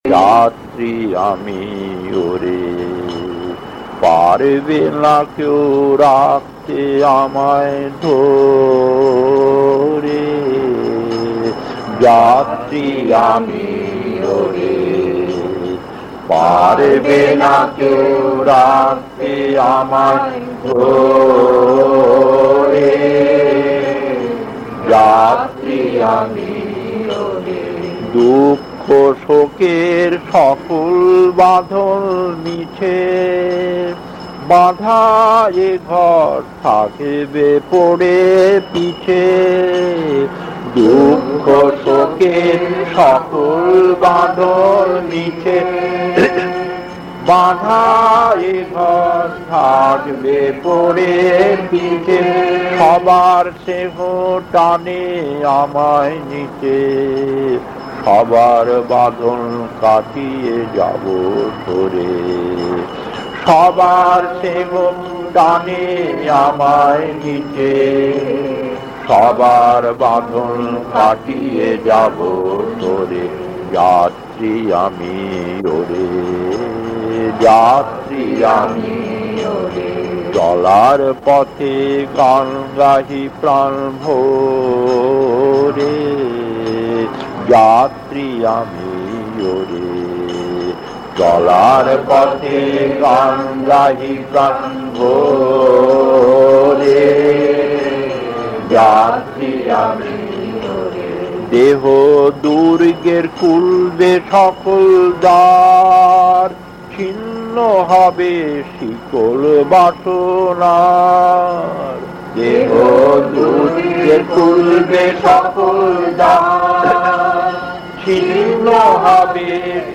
Kirtan A5-1 Thakur Niwas, Mumbai 1978 1.